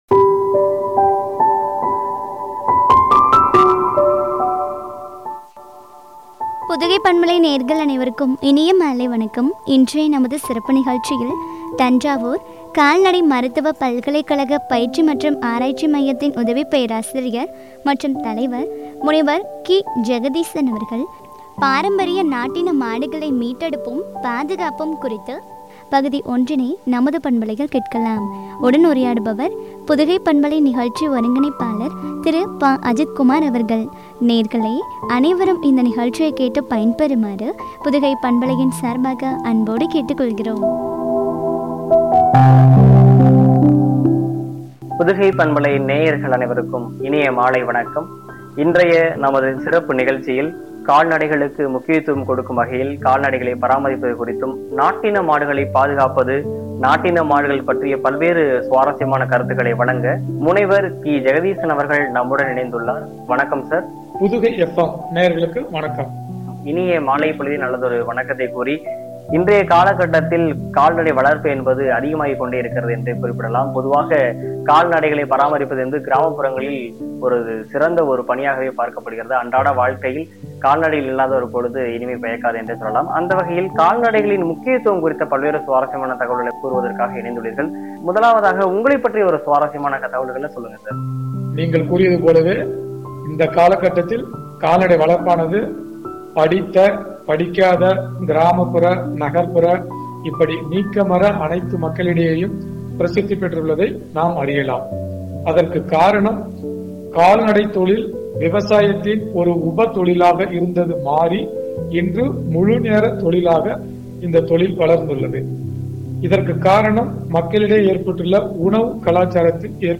(பகுதி 1) குறித்து வழங்கிய உரையாடல்.